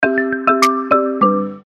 короткие
звонкие
ксилофон
Легкий и ненавязчивый звук на сообщения, уведомления